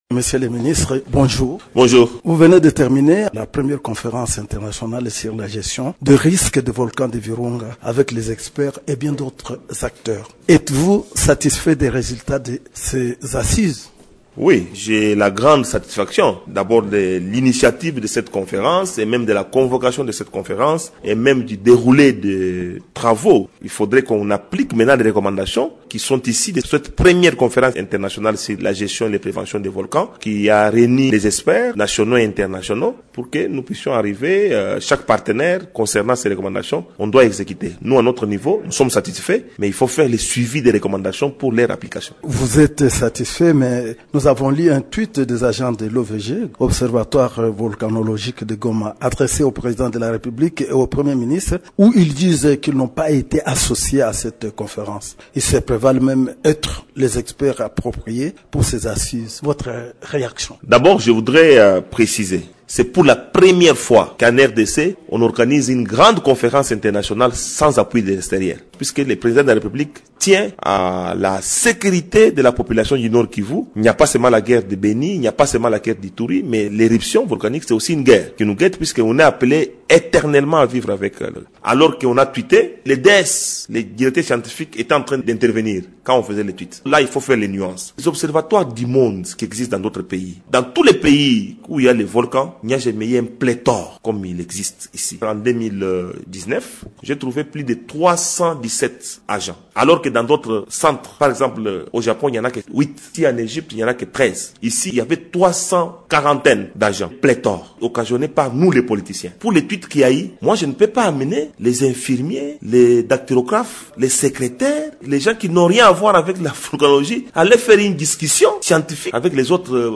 Il l’a dit dans une interview accordée à Radio Okapi.